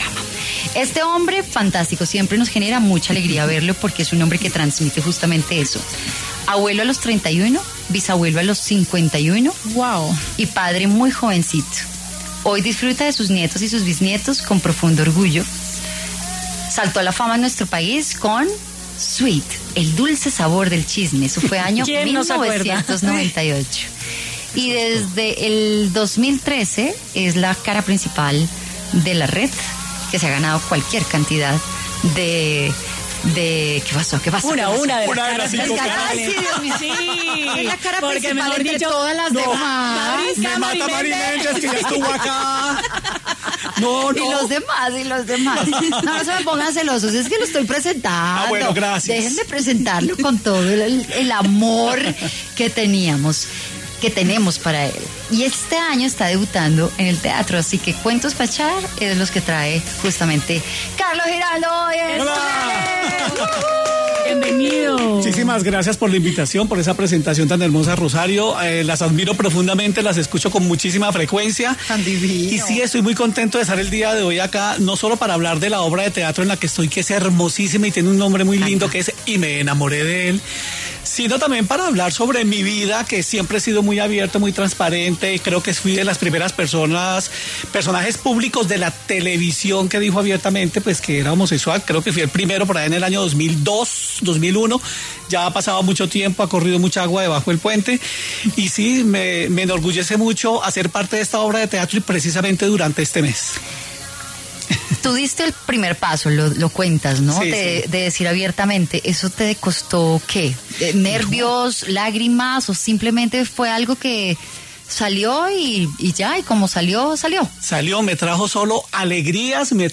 El reconocido periodista y presentador colombiano compartió en Mujeres W detalles sobre su carrera profesional, sus experiencias personales y su más reciente proyecto teatral.